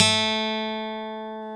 Index of /90_sSampleCDs/Roland L-CDX-01/GTR_Steel String/GTR_ 6 String
GTR 6-STR30V.wav